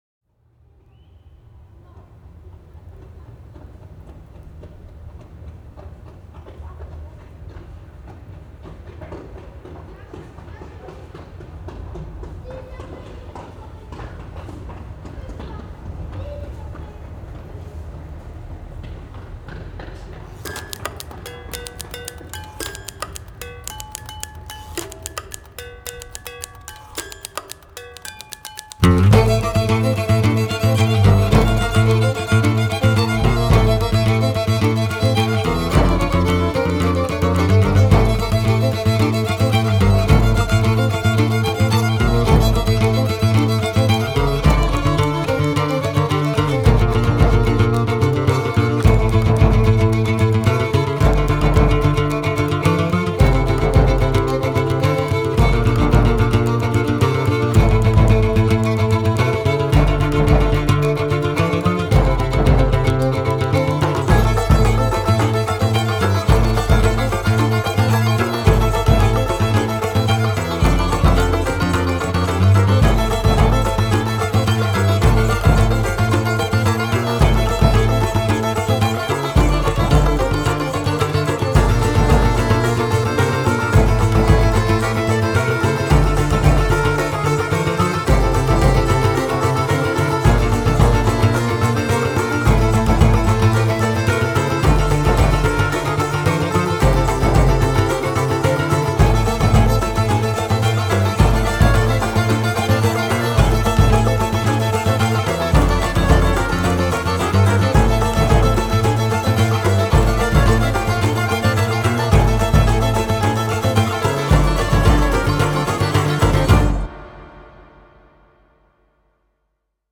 Genre: World Music, Alternative, Neo-Classical, Neo-Folk